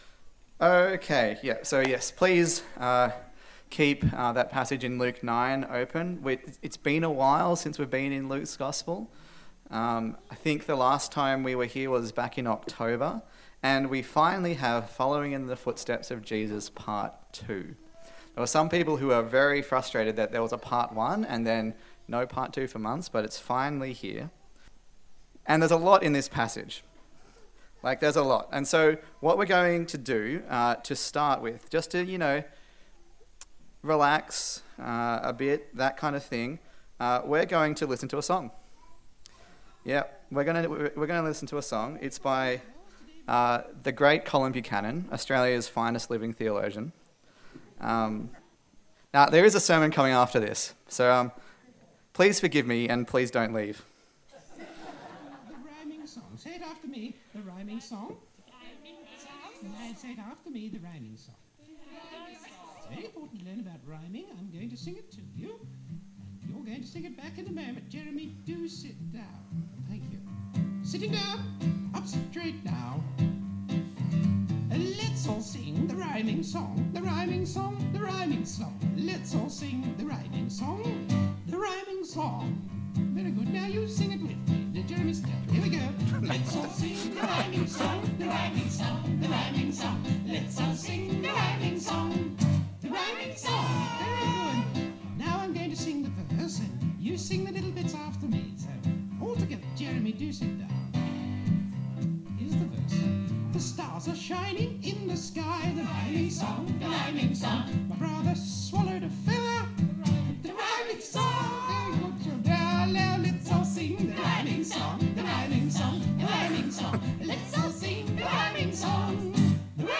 Luke Passage: Luke 9:37-62 Service Type: Sunday Service A sermon in the series on the Gospel of Luke